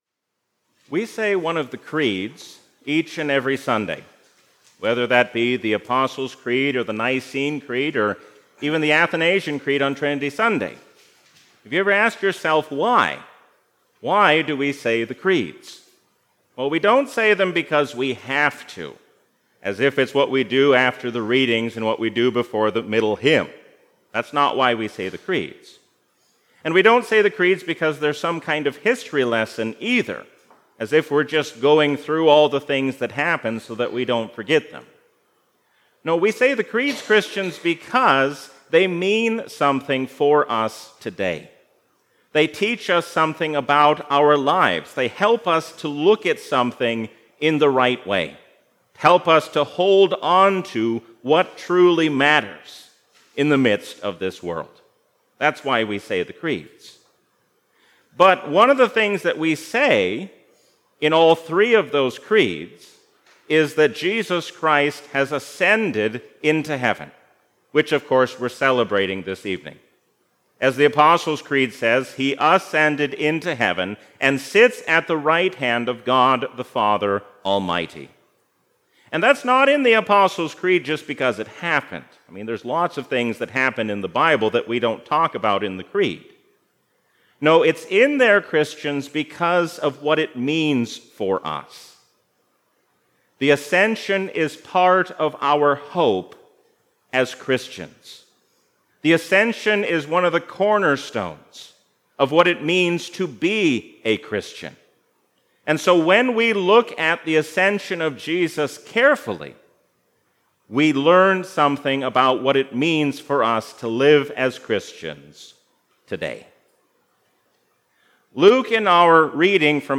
A sermon from the season "Easter 2022." Jesus going up into heaven teaches us how to live as Christians in the world.